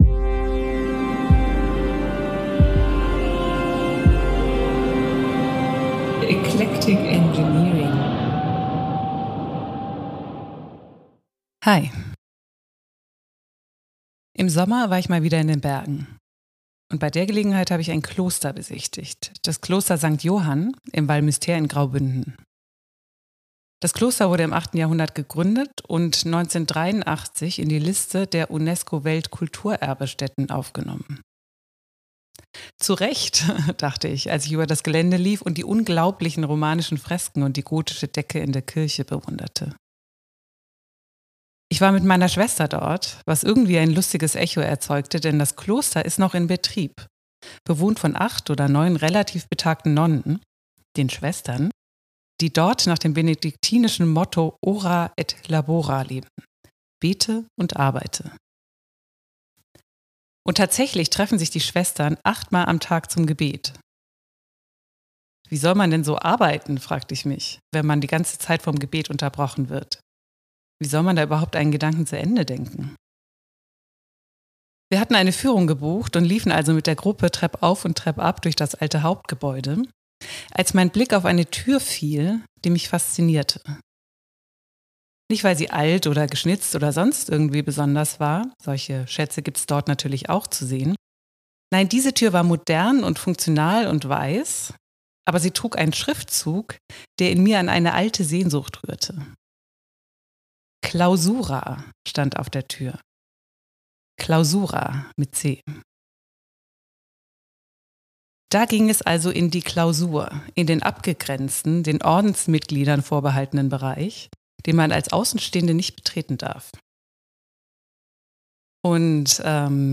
Gespräche und Monologe über Philosophie, Kunst, feministische Theorie und Psychoanalyse.